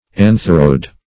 Antheroid \An"ther*oid\, a.